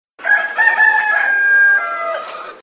henwav.mp3